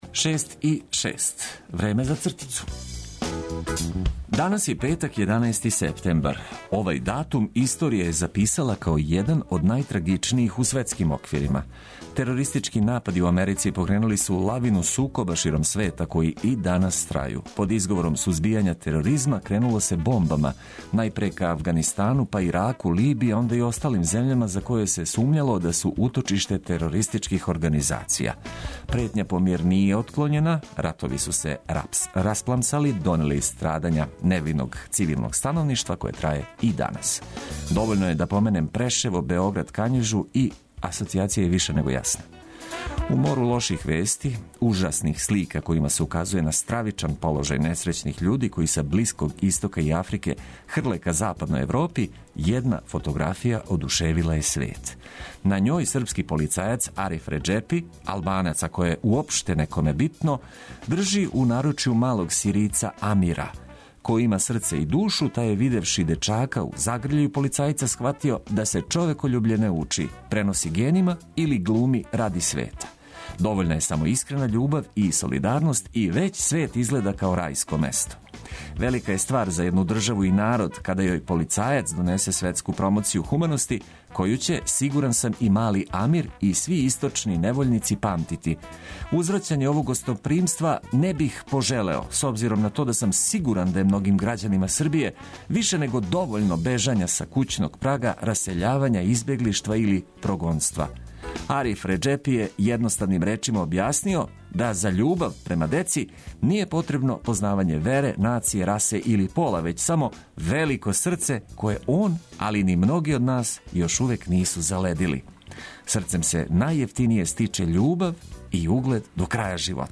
Нека овај петак почне уз ведру музику коју ћемо прошарати корисним информацијама.